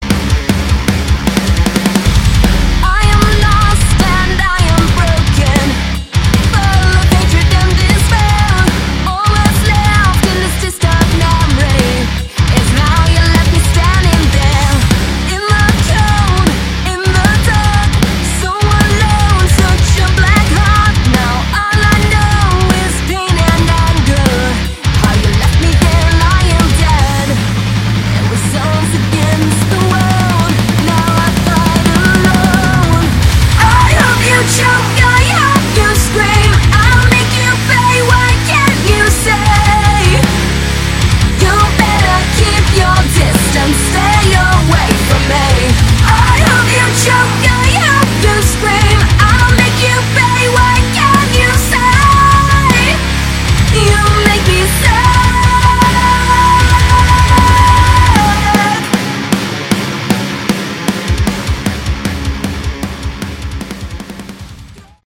Category: Melodic Metal
lead vocals, guitars
bass, backing vocals
drums